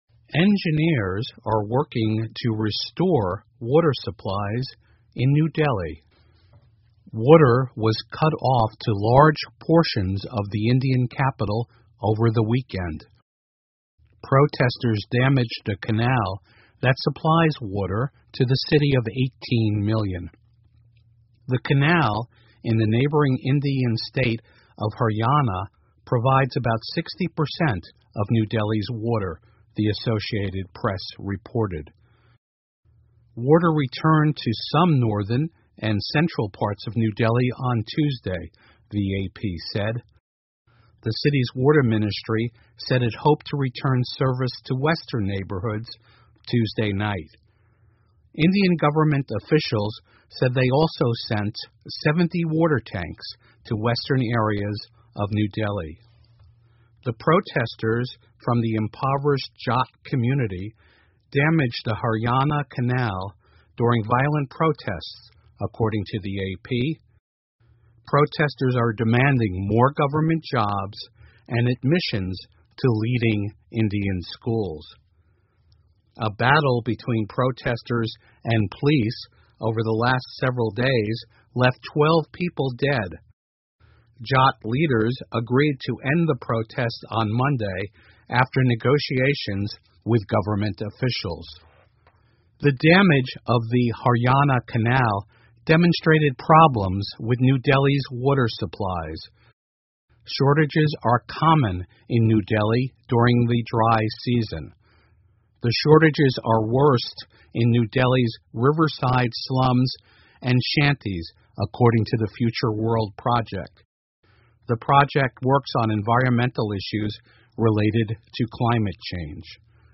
VOA慢速英语--新德里水骚乱后尝试恢复供水 听力文件下载—在线英语听力室